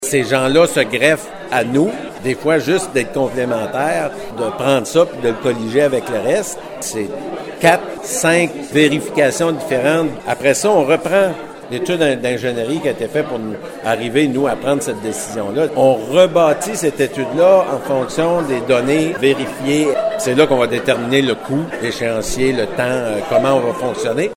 Le maire de Percé affirme que plusieurs scientifiques uniront leurs connaissances afin de déterminer la meilleure méthode de construction pour assurer la protection de la côte de Percé. Le maire André Boudreau :